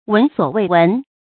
注音：ㄨㄣˊ ㄙㄨㄛˇ ㄨㄟˋ ㄨㄣˊ